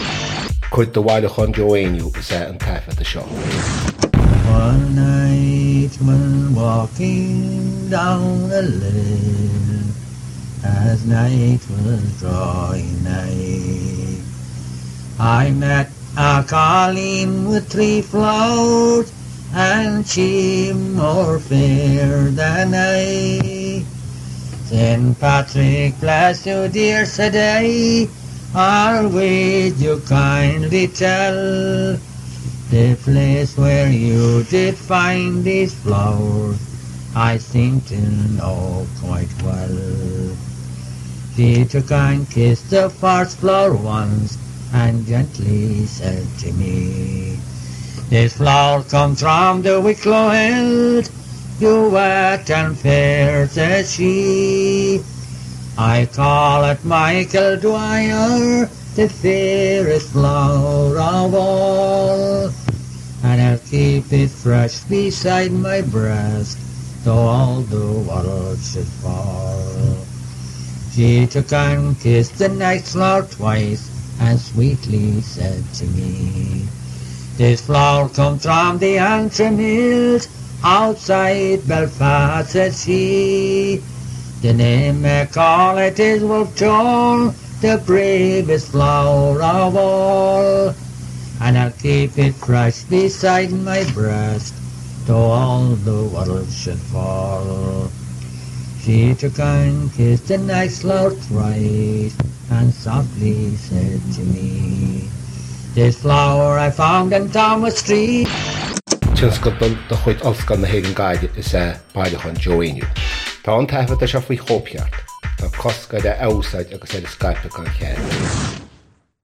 • Catagóir (Category): song.
• Ainm an té a thug (Name of Informant): Joe Heaney.
• Suíomh an taifeadta (Recording Location): University of Washington, United States of America.
This recording is cut off at the end, but the remainder – indicated in square brackets – has been supplied from a file of texts kept by the Joe Heaney Collection, University of Washington, United States of America.